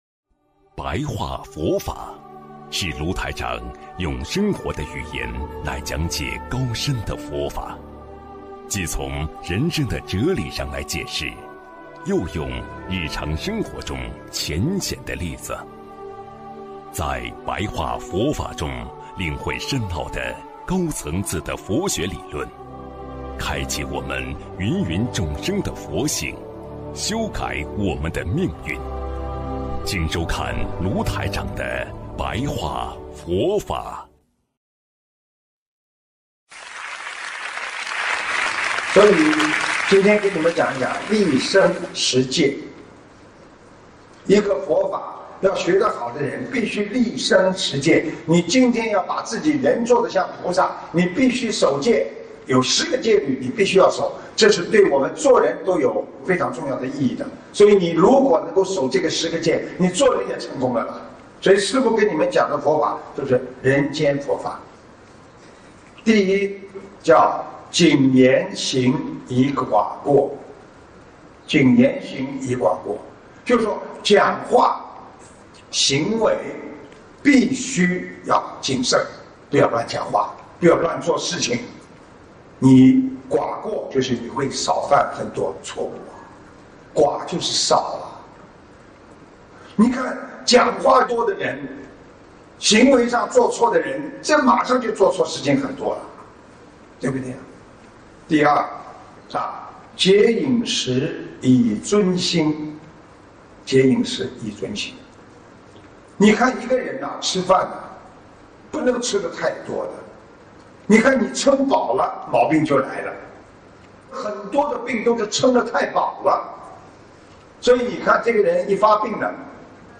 广播讲座音频合集（1-91集） - 广播讲座 - 普照 - Powered by Discuz!